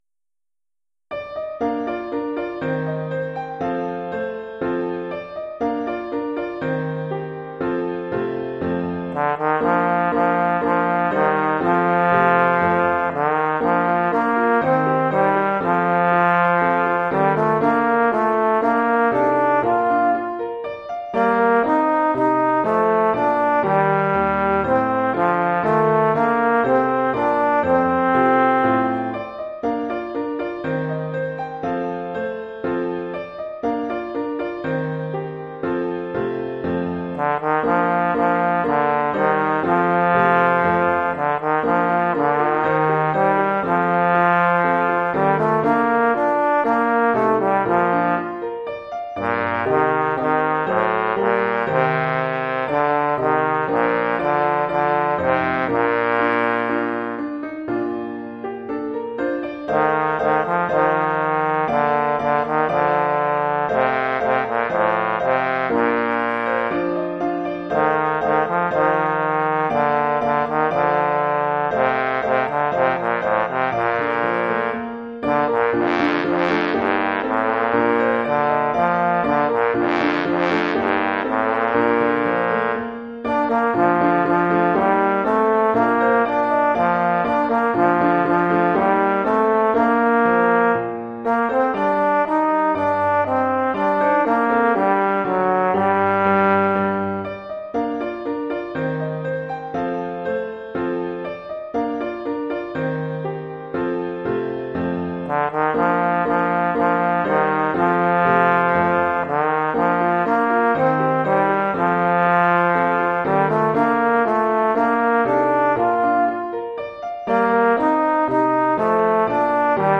Oeuvre pour trombone et piano.